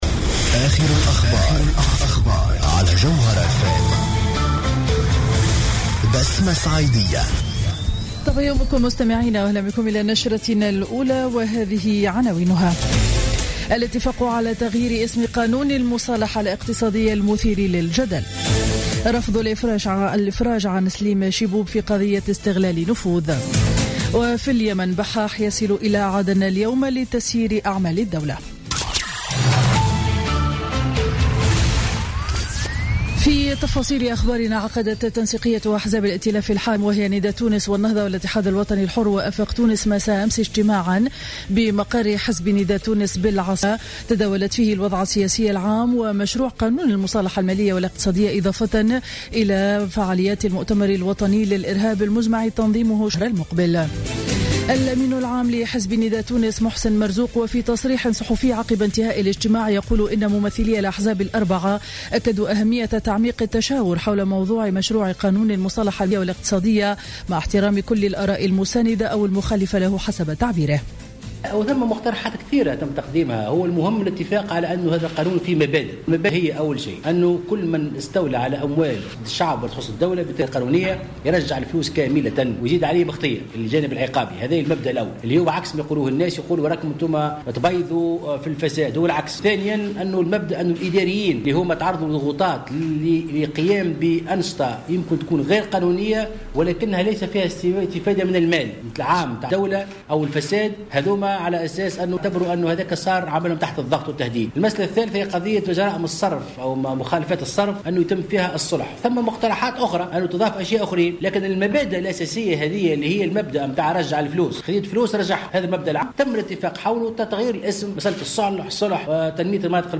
نشرة أخبار السابعة صباحا ليوم الإربعاء 16 سبتمبر 2015